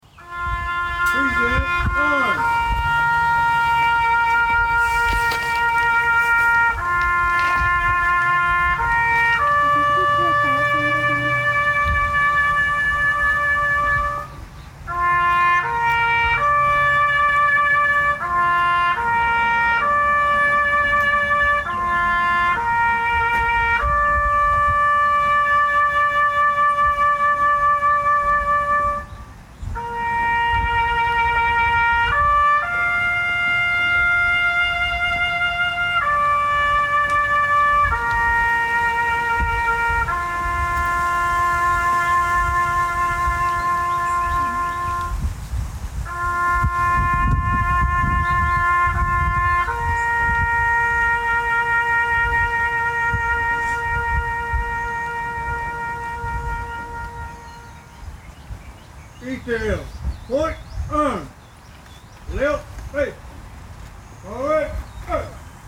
Veterans Remembered at Ceremony in Ramona